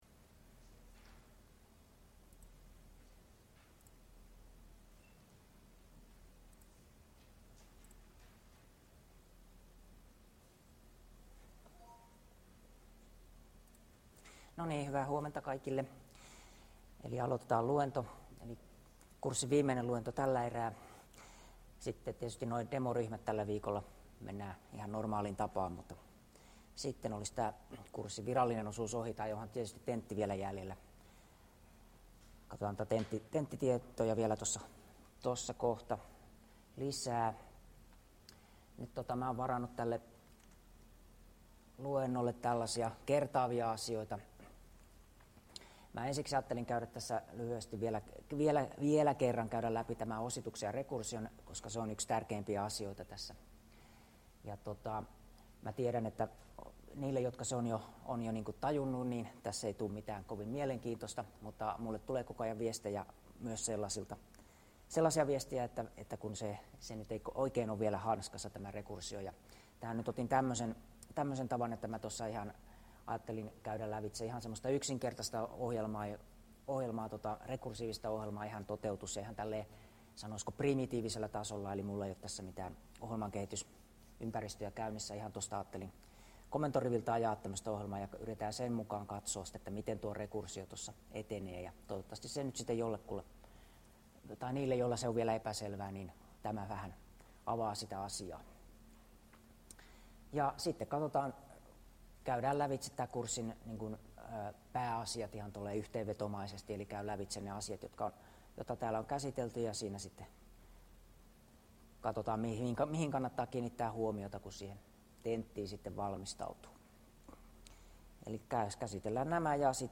Luento 14 — Moniviestin